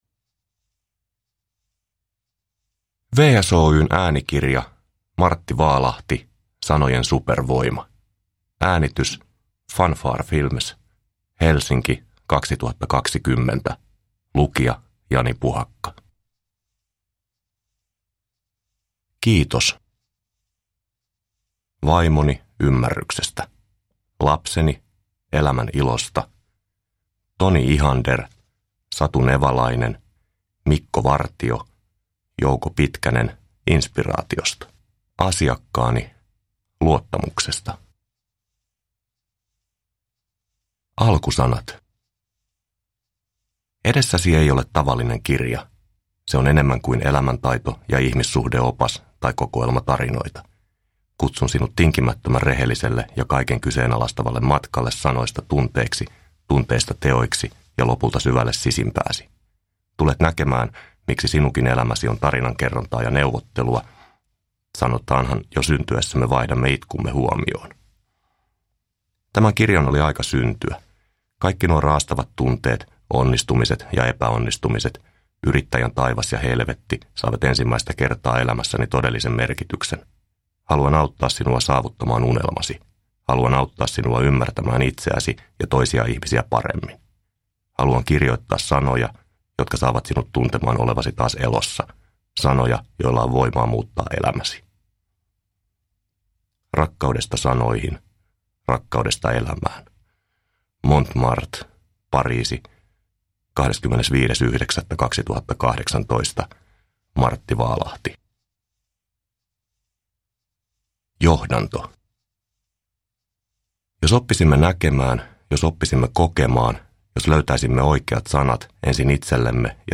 Sanojen supervoima – Ljudbok – Laddas ner